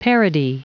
Prononciation du mot parody en anglais (fichier audio)
Prononciation du mot : parody